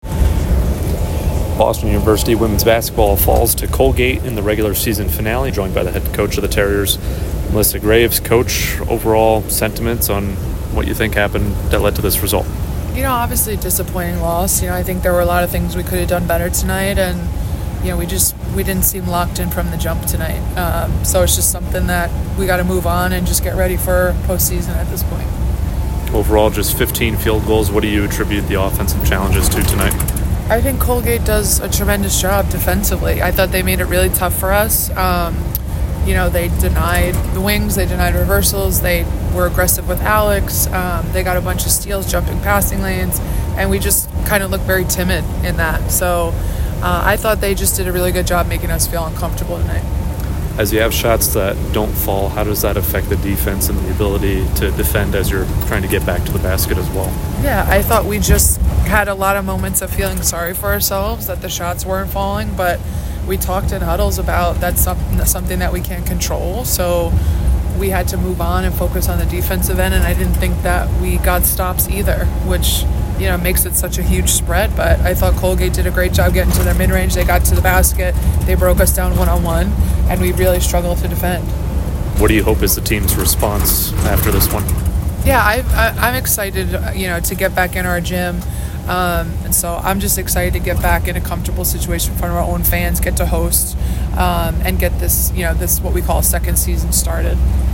WBB_Colgate_2_Postgame.mp3